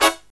HIGH HIT02-R.wav